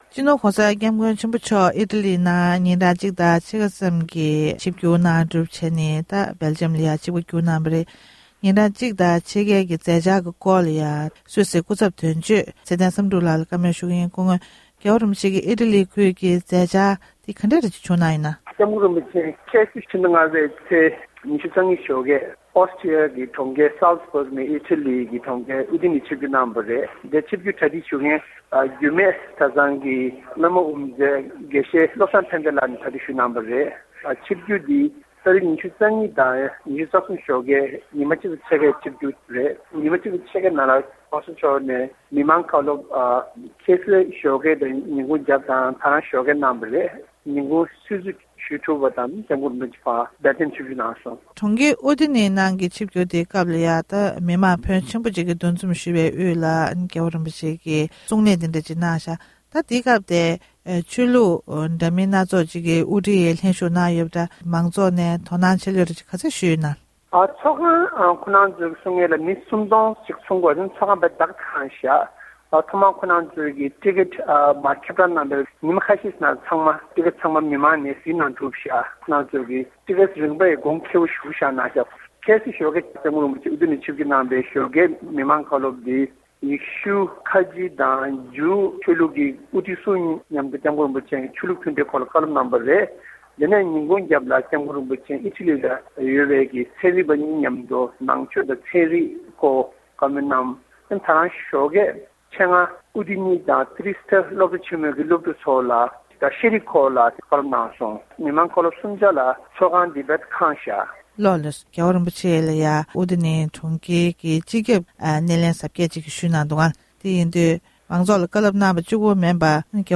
སྒྲ་ལྡན་གསར་འགྱུར།
གནས་འདྲི